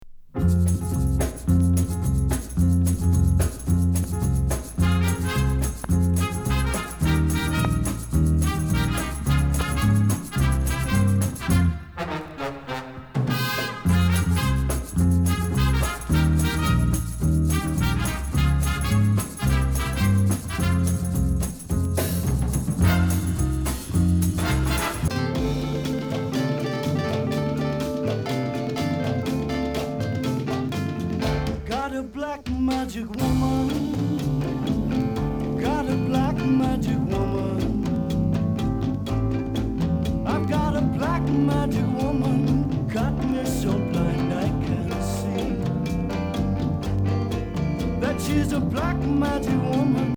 ソニーのSQ方式4チャンネルステレオ・チェックレコード。
Japanese,AudioTest,Jazz,Funk,Pops,Groove ♪LISTEN LABEL/PRESS